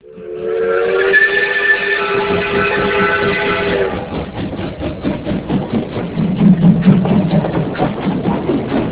Train.wav